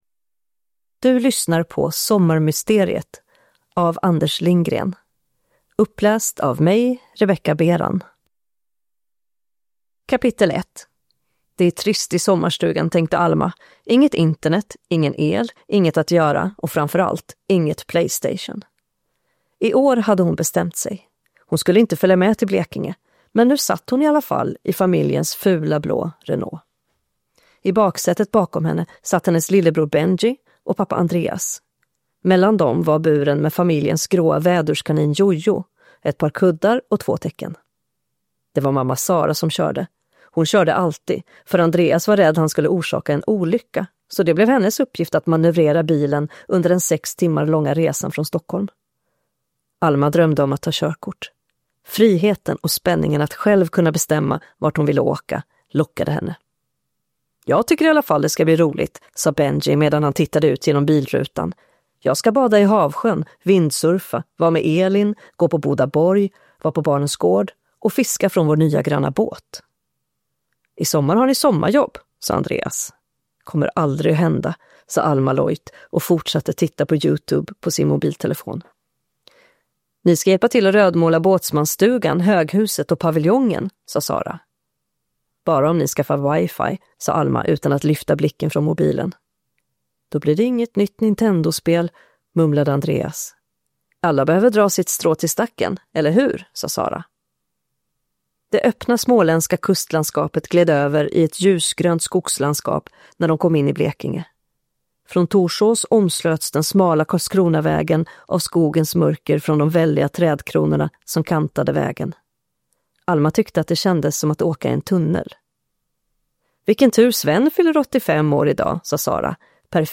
Sommarmysteriet – Ljudbok